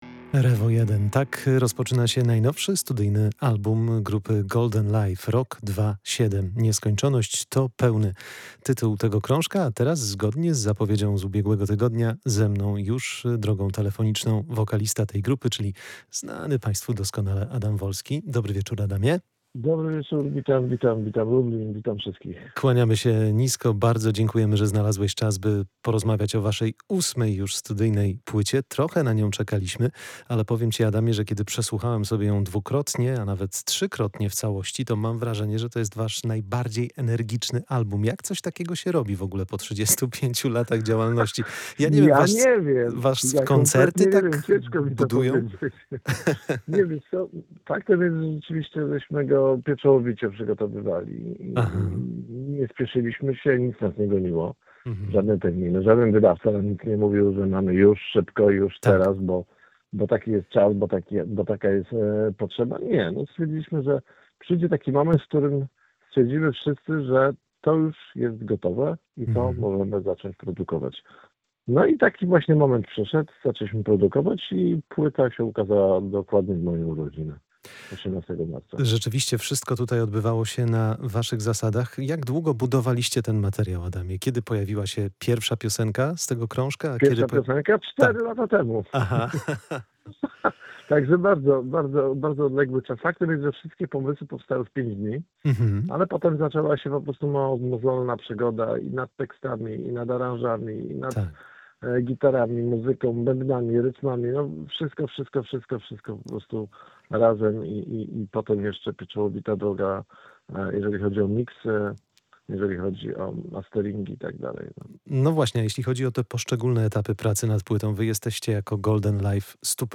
Wydział Muzyki: Nowy studyjny album Golden Life, czyli przebojowość i rockowa energia [POSŁUCHAJ ROZMOWY]